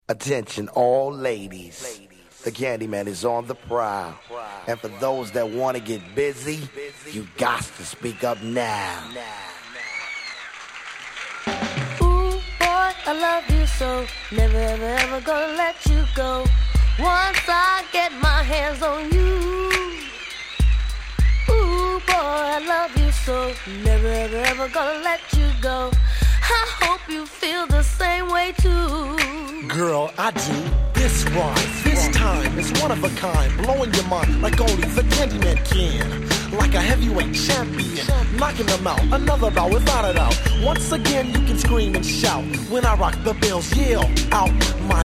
90' Big Hit Hip Hop !!
もうコレはHip Hopって言うよりはR&Bですよね！
90's ヒップホップ